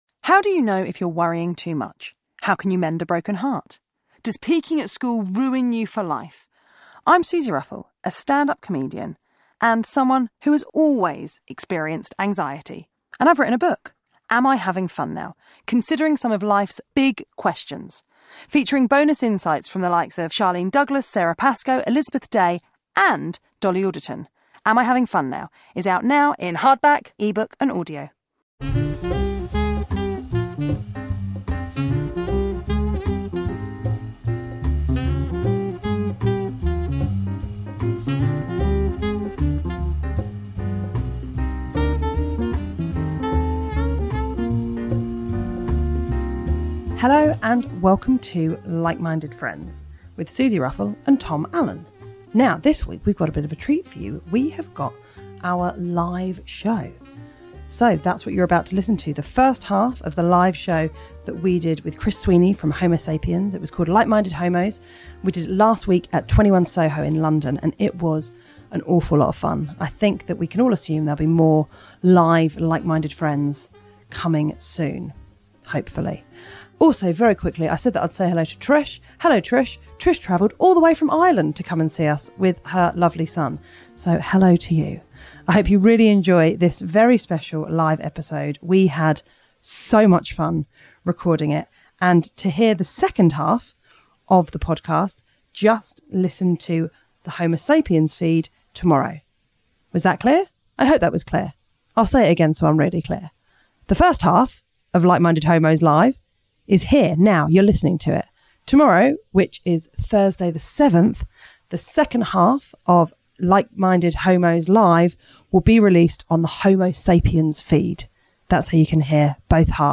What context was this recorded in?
Like Minded Homos LIVE SHOW - Part 1